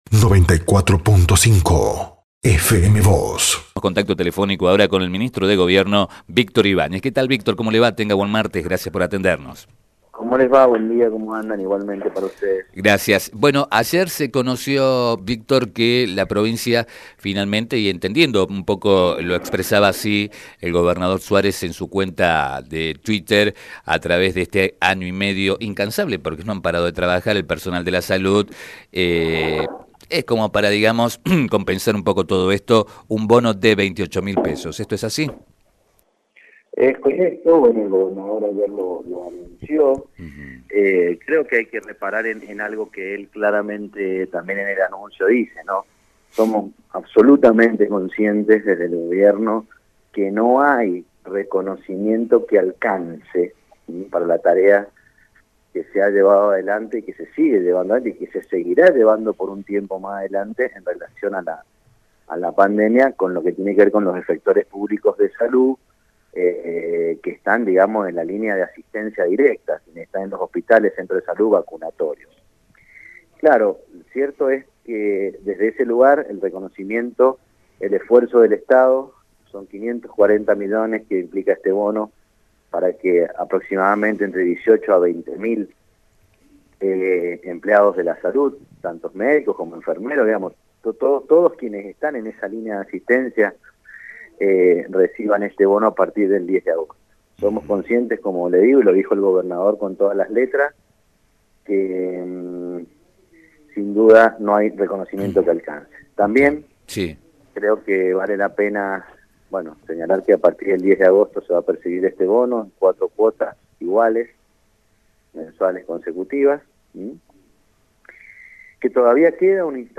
Al respecto, el ministro de Gobierno, Trabajo y Justicia de Mendoza, Víctor Ibáñez, dijo a FM Vos (94.5) y Diario San Rafael que “hay que reparar en algo que el Gobernador dice, que somos absolutamente conscientes desde el Gobierno que no hay reconocimiento que alcance para la tarea que vienen llevando adelante los efectores públicos de salud que están en la línea de asistencia directa, esto es hospitales, centros de salud y vacunatorios”.